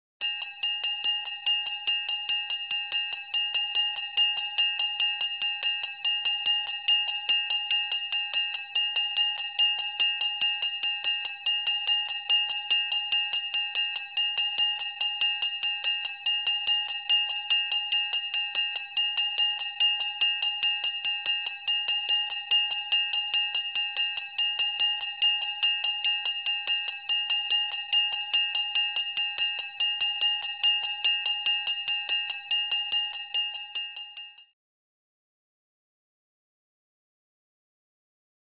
Old Fire Truck | Sneak On The Lot
Antique Fire Engine Or Cable Car Bell Clangs Close Perspective.